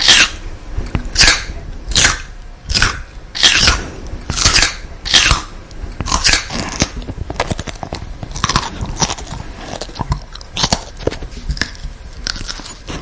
描述：吃松脆的胡萝卜会增加db水平
Tag: 饮食 脆脆的 蔬菜 胡萝卜 食品